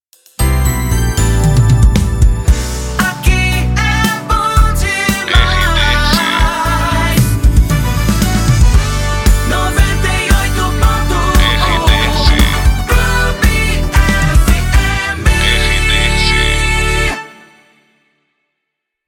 Entrada de Bloco